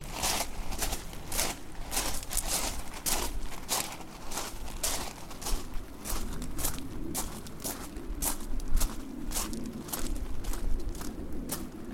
walk.wav